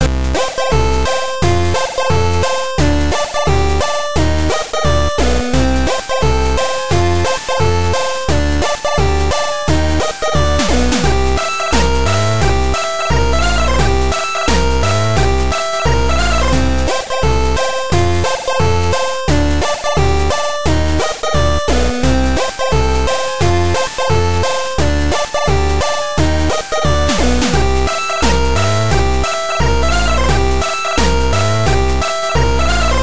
This file is an audio rip from a(n) Game Boy Color game.